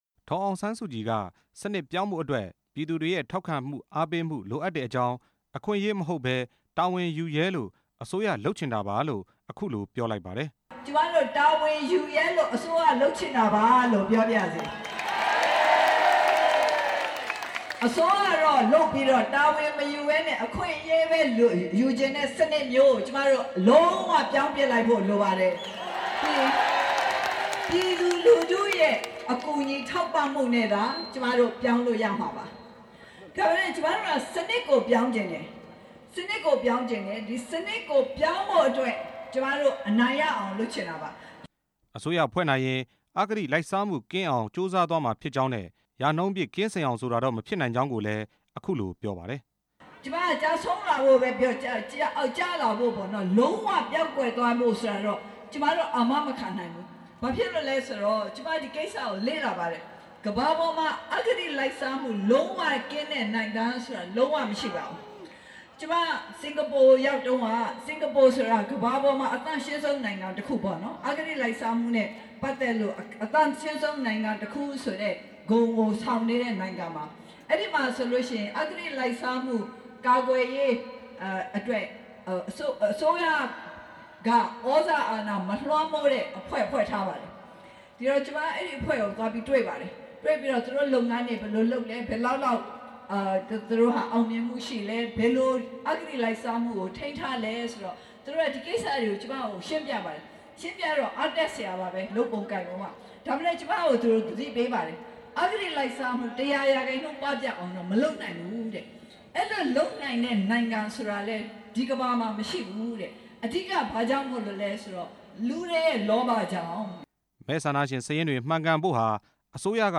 အမျိုးသားဒီမိုကရေစီအဖွဲ့ချုပ် ဥက္ကဌ ဒေါ်အောင်ဆန်းစုကြည်ဟာ ဒီကနေ့ ရန်ကုန်တိုင်း ဒေသကြီး မှော်ဘီမြို့နယ်က မြောင်းတကာကျေးရွာ မှာ ရွေးကောက်ပွဲအောင်နိုင်ရေး ဟောပြောပွဲကျင်းပခဲ့ပါတယ်။